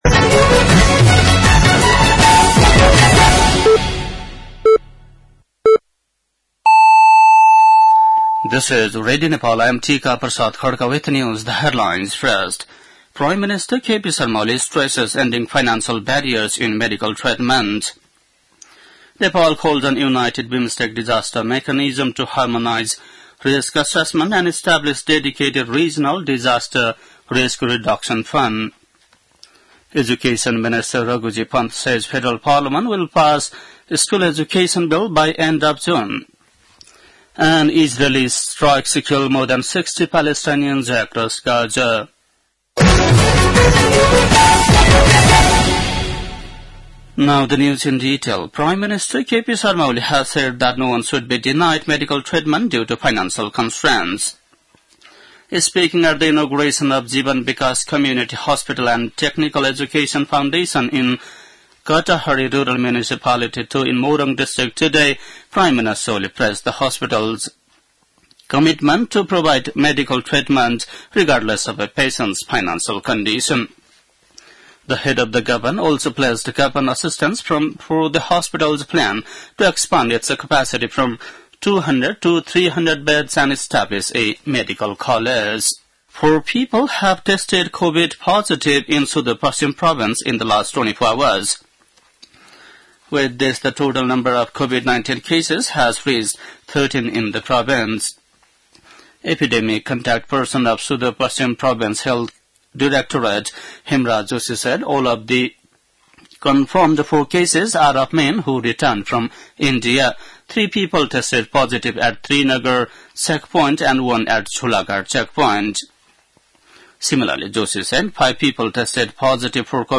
बेलुकी ८ बजेको अङ्ग्रेजी समाचार : २४ जेठ , २०८२
8.-pm-english-news-.mp3